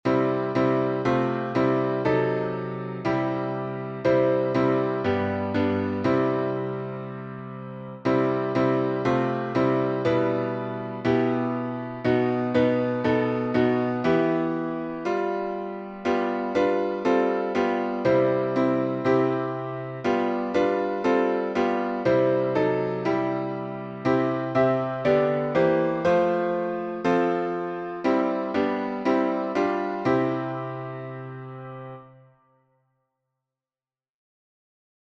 Words by James Montgomery (1771-1854) Tune PENITENCE by Spencer Lane (1843-1903) Key signature: C major
Time signature: 4/4